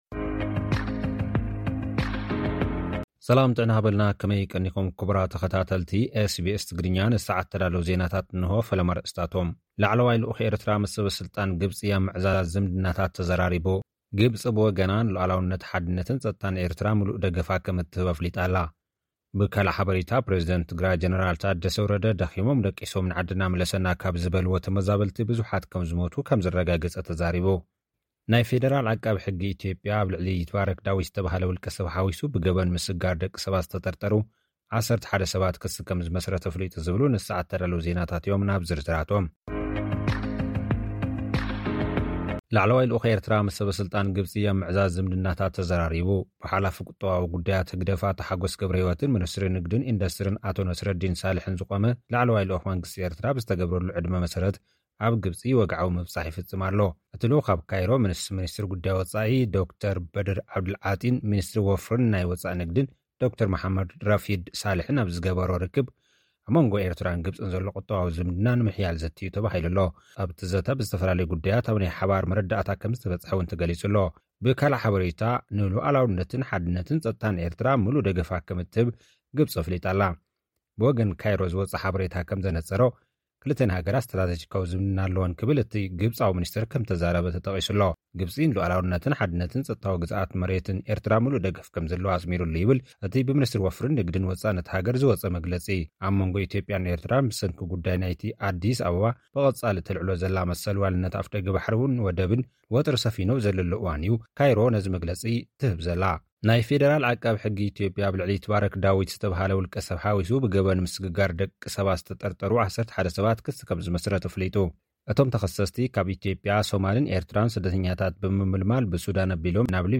SBS Tigrinya Homeland Report